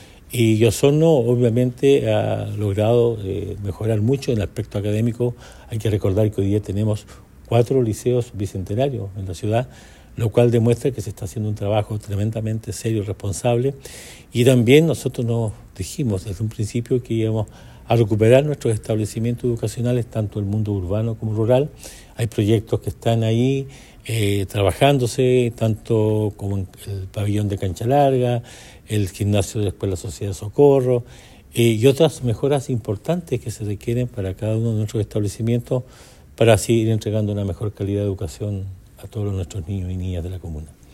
Carrillo subrayó, además, el esfuerzo conjunto entre el municipio y el Departamento de Administración de la Educación Municipal, que ha permitido llevar adelante una serie de iniciativas que han transformado las condiciones de las escuelas en Osorno.
11-noviembre-24-emeterio-carrillo-educacion.mp3